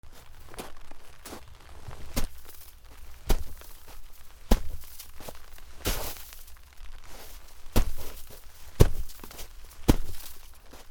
雪を蹴る
/ M｜他分類 / L35 ｜雪・氷
『ザ』